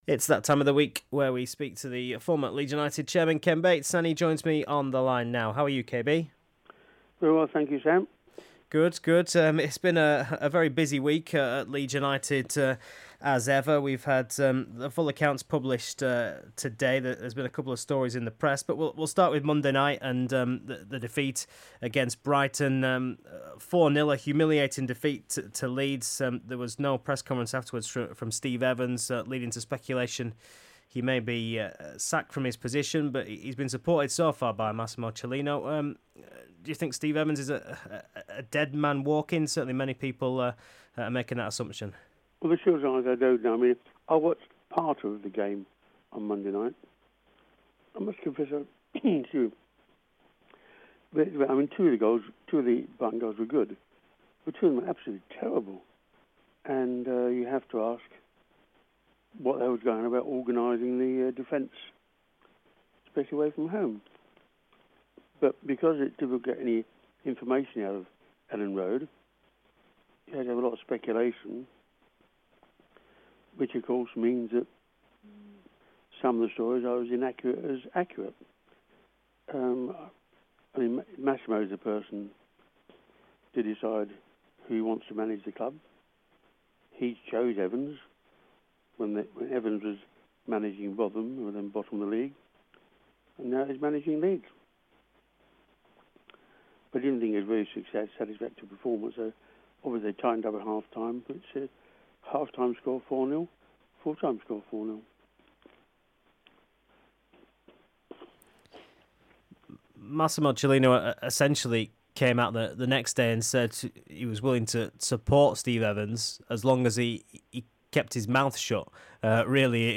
Former Leeds chairman Ken Bates talks Steve Evans, Massimo Cellino and accounts in his latest interview.